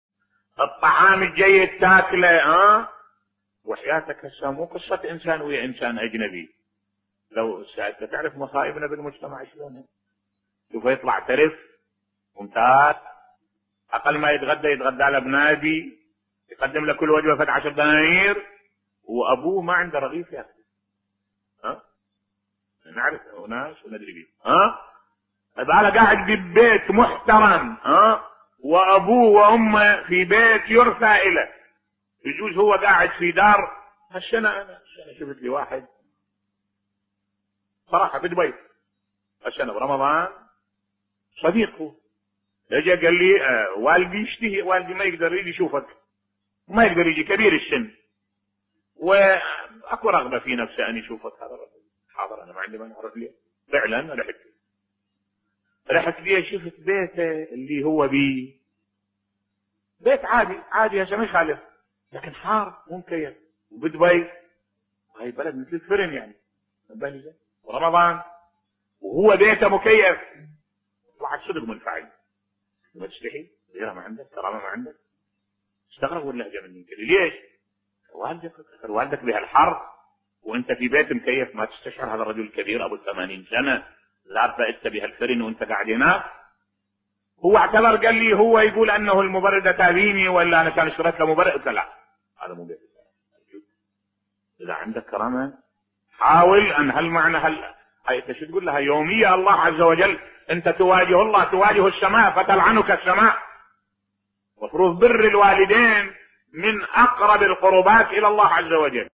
ملف صوتی قصة الشيخ احمد الوائلي مع العاق لوالده بصوت الشيخ الدكتور أحمد الوائلي